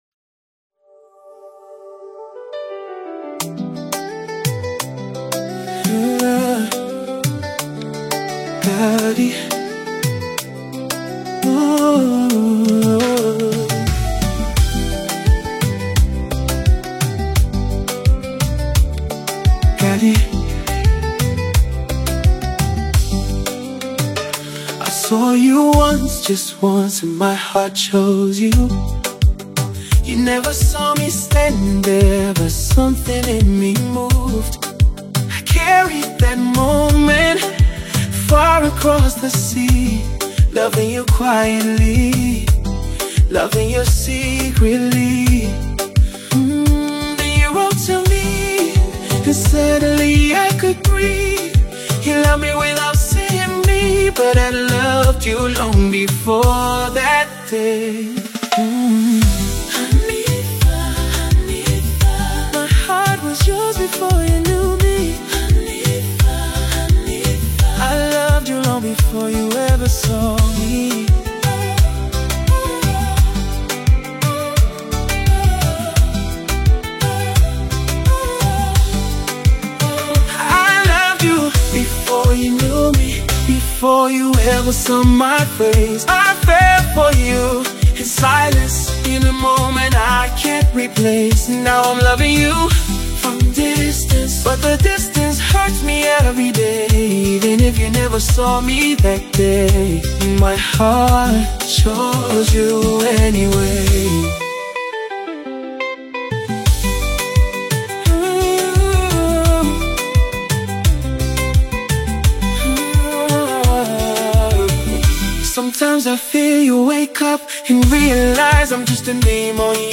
blending traditional influences with modern sounds